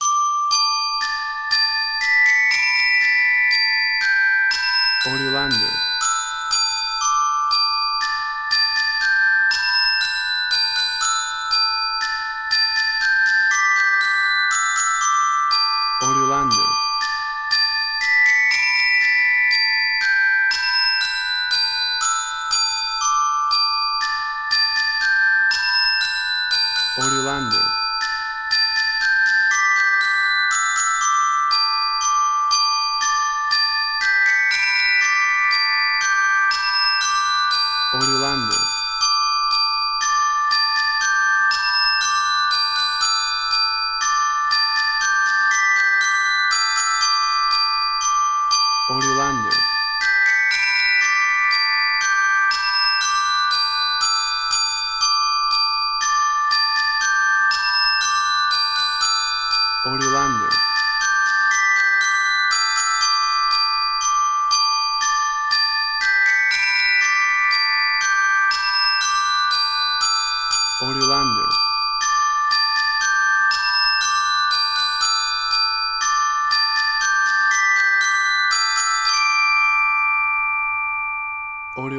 A playful and exciting Glockenspiel version
kid music
Tempo (BPM): 119